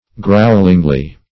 growlingly - definition of growlingly - synonyms, pronunciation, spelling from Free Dictionary Search Result for " growlingly" : The Collaborative International Dictionary of English v.0.48: Growlingly \Growl"ing*ly\, adv.
growlingly.mp3